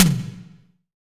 Index of /90_sSampleCDs/Best Service - Real Mega Drums VOL-1/Partition G/SDS V TOM ST